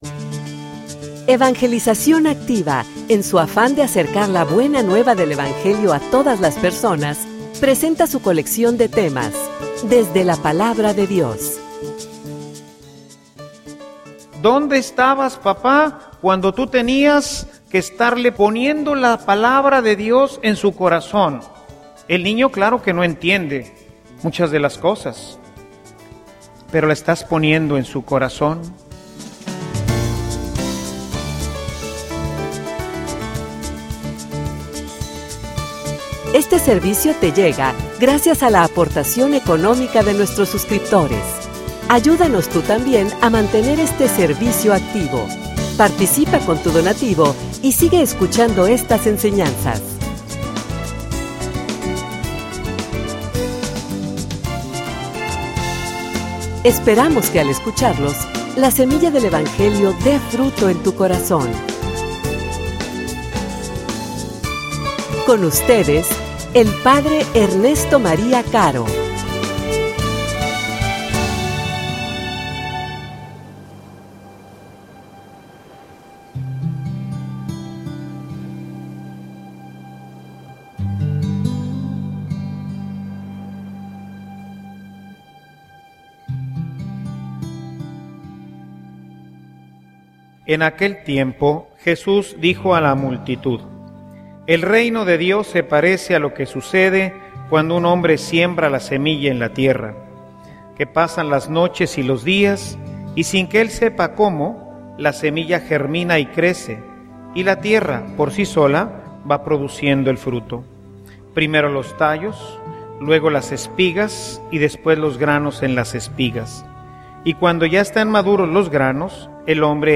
homilia_Fijate_como_siembras.mp3